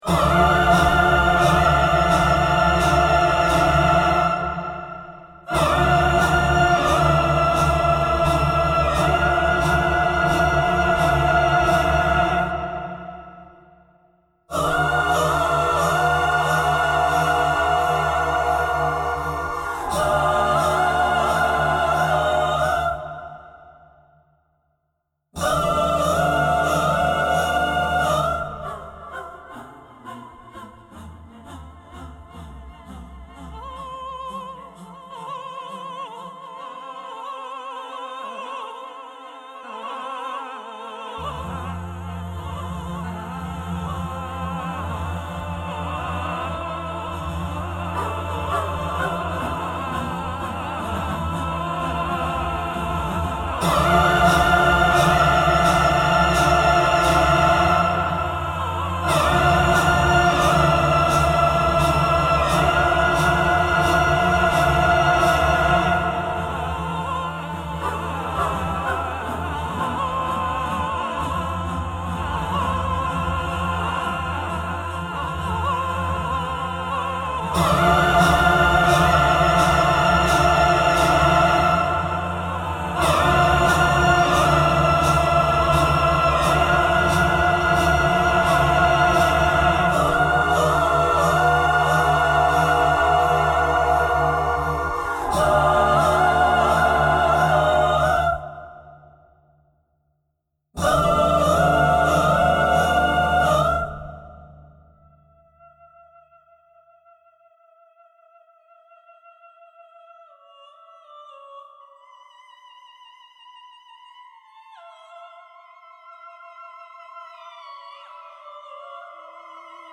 Opera kameralna
adaptacja elektroakustyczna